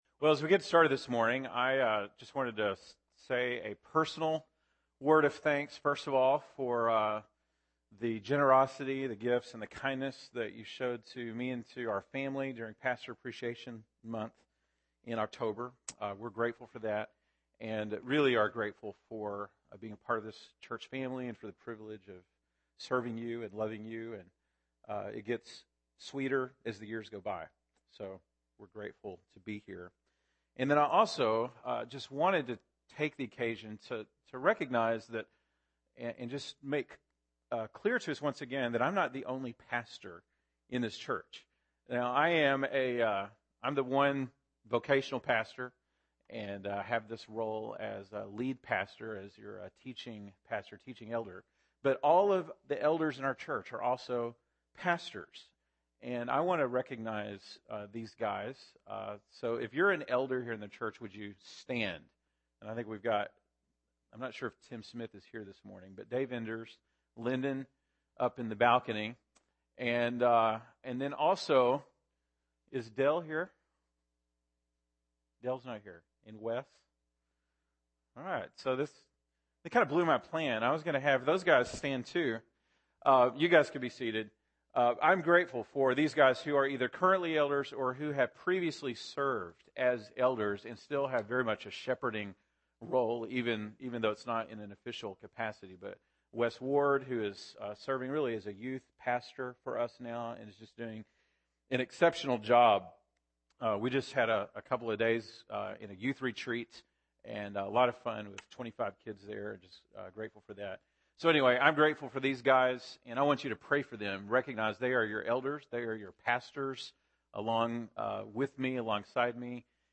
November 8, 2015 (Sunday Morning)